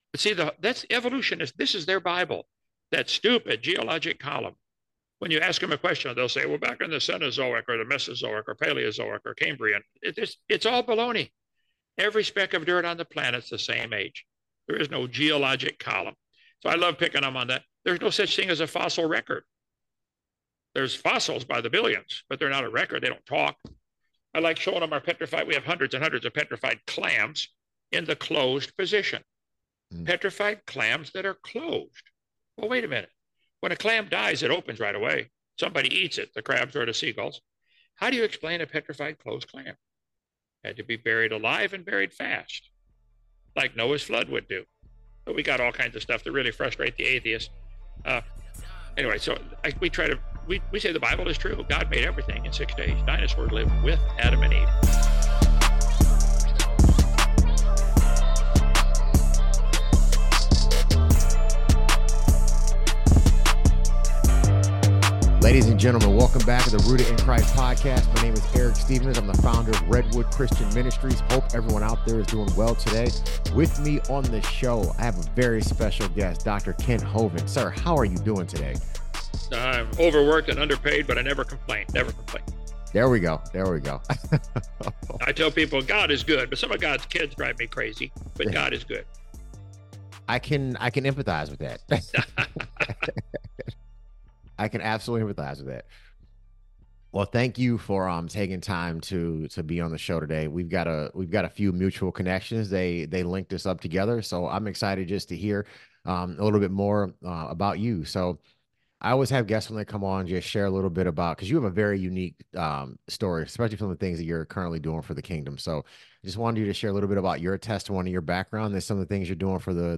In this episode of The Rooted in Christ Podcast, we're sitting down with Dr. Kent Hovind, a creationist and the founder of Dino Adventure Land.